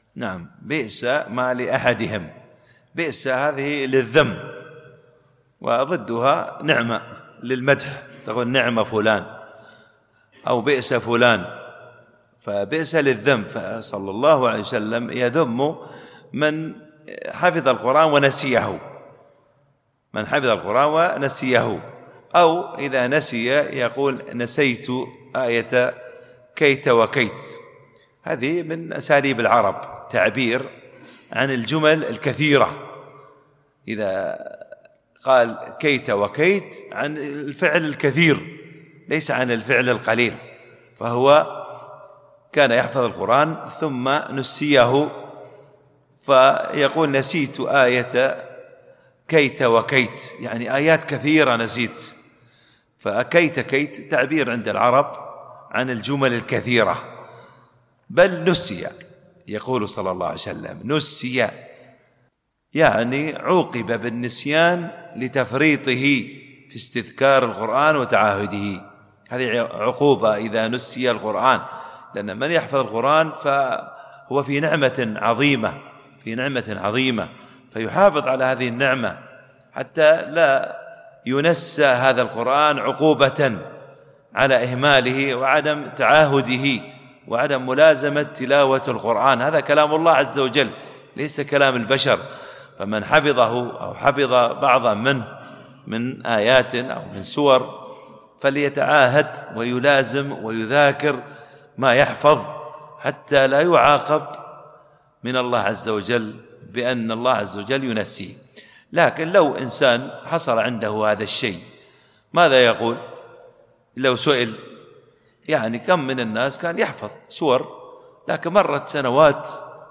التنسيق: MP3 Mono 44kHz 117Kbps (VBR)